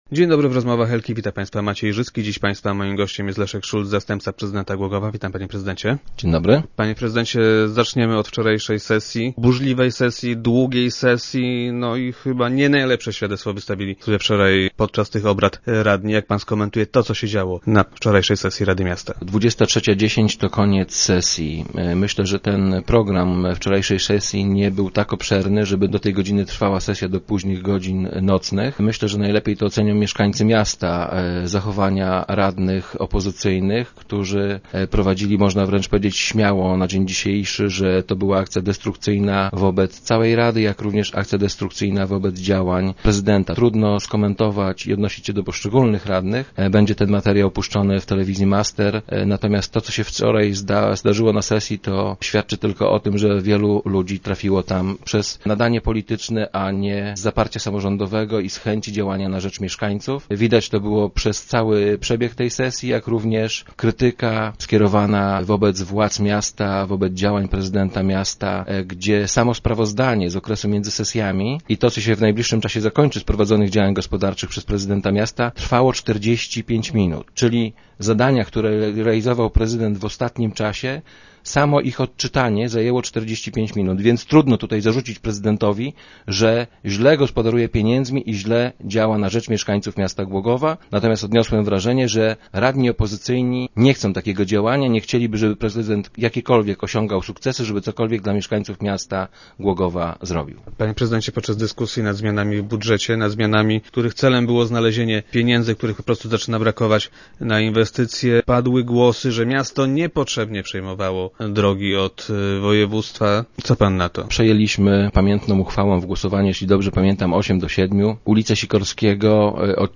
- Od dawna usiłujemy rozwiązać ten problem - powiedział Leszek Szulc, zastępca prezydenta Głogowa.